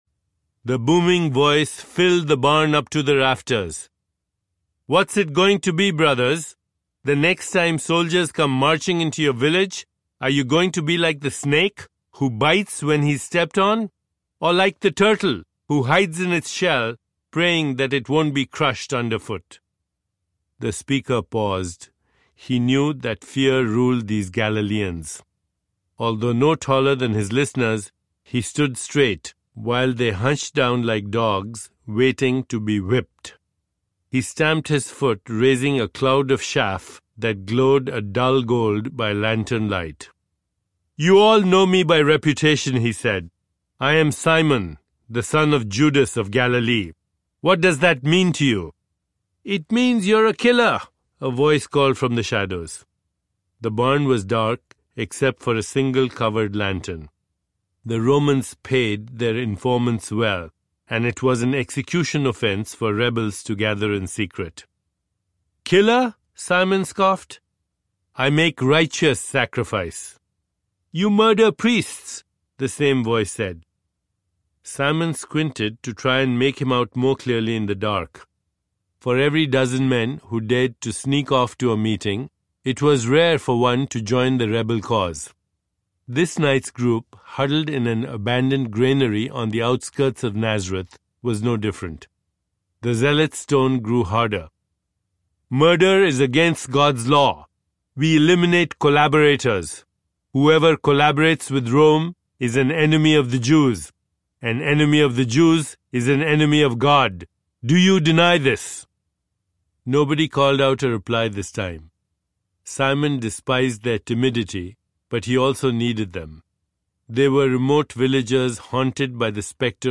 Jesus Audiobook
Narrator
Deepak Chopra
7.0 Hrs. – Unabridged